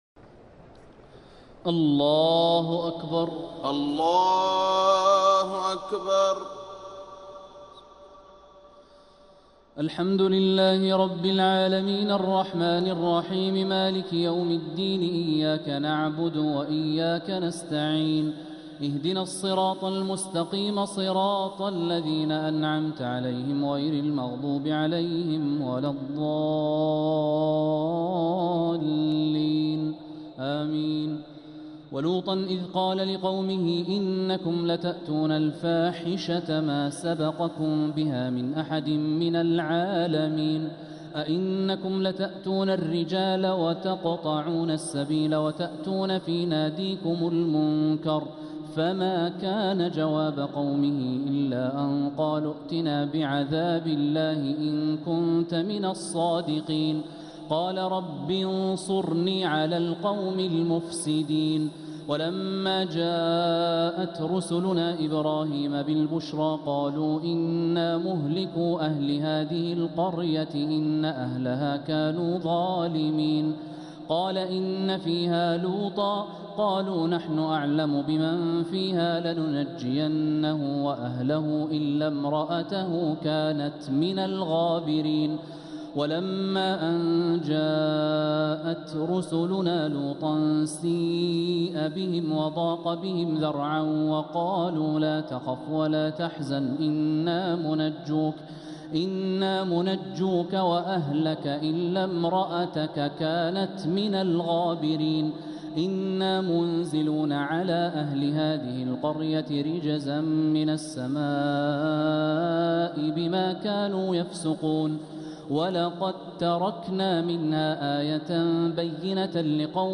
تهجد ليلة 23 رمضان 1446هـ من سورتي العنكبوت (28-69) و الروم (1-32) | Tahajjud 23rd night Ramadan 1446H Surah Al-Ankaboot and Ar-Room > تراويح الحرم المكي عام 1446 🕋 > التراويح - تلاوات الحرمين